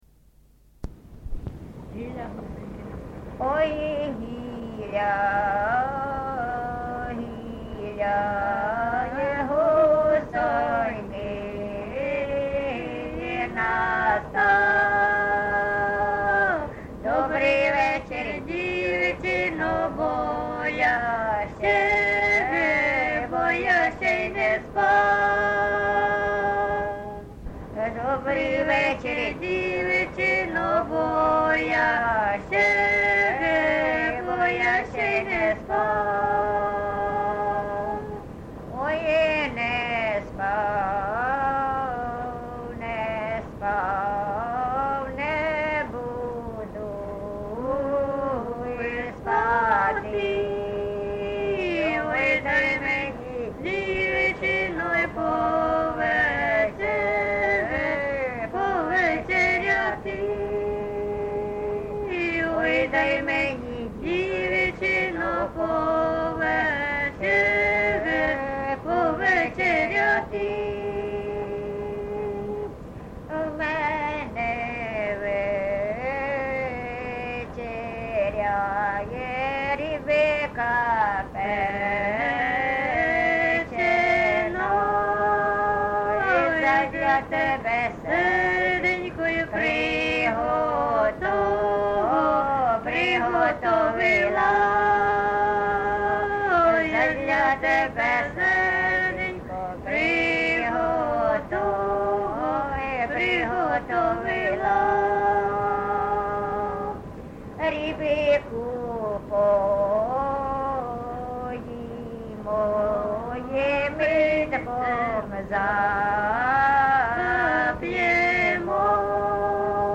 ЖанрПісні з особистого та родинного життя
Місце записус. Харківці, Миргородський (Лохвицький) район, Полтавська обл., Україна, Полтавщина